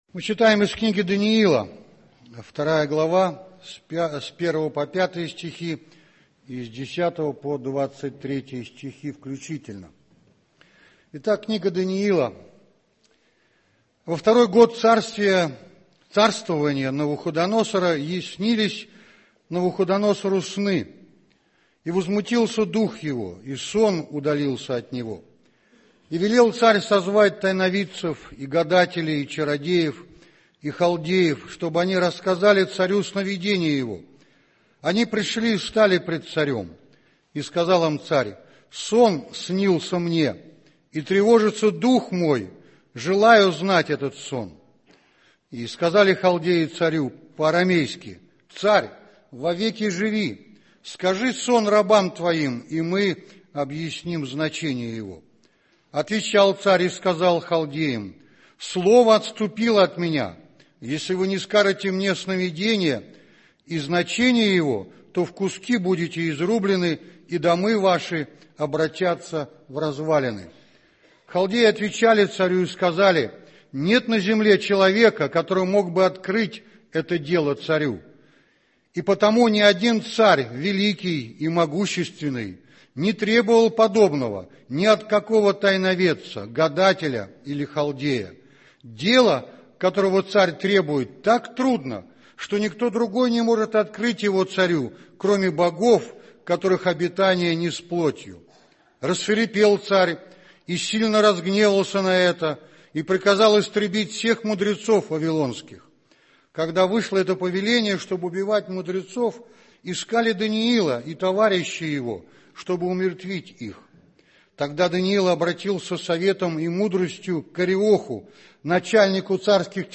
Текст из Библии: Даниил. 2 глава Скачать проповедь